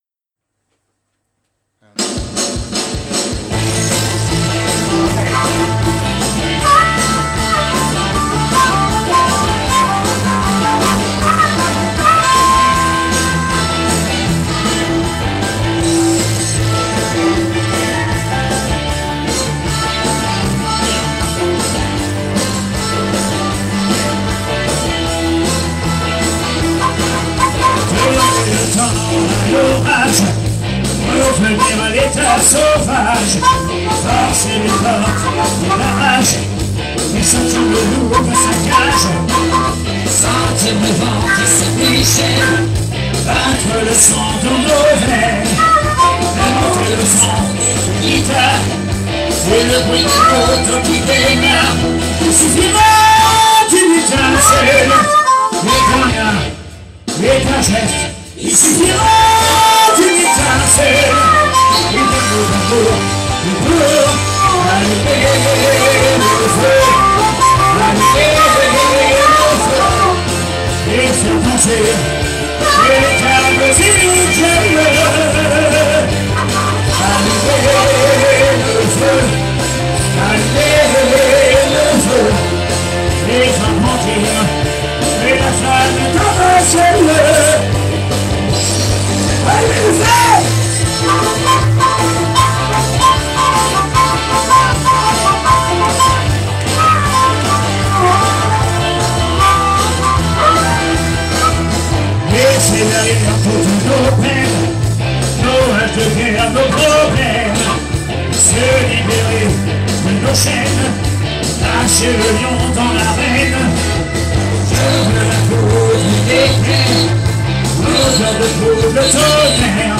SOIREES BLUES-ROCK RETROSPECTIVE
maquettes